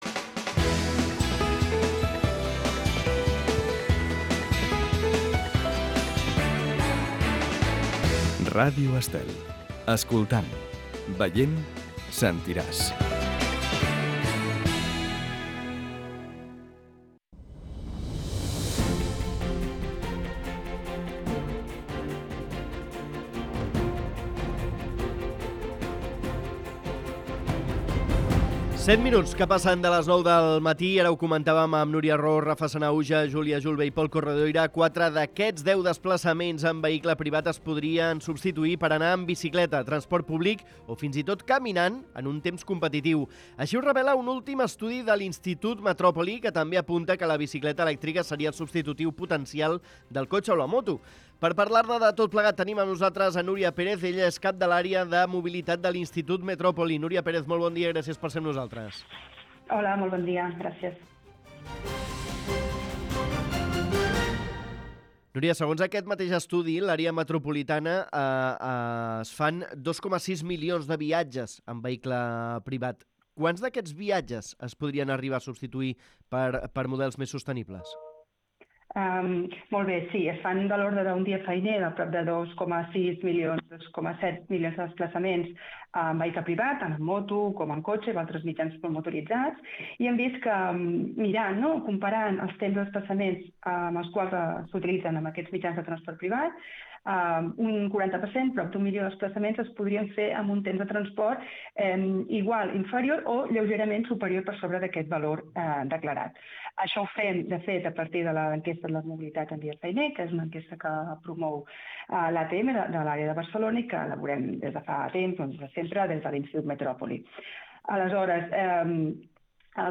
En una entrevista al programa La Caravana de Ràdio Estel